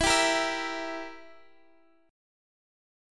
Edim Chord